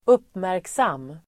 Uttal: [²'up:märksam:]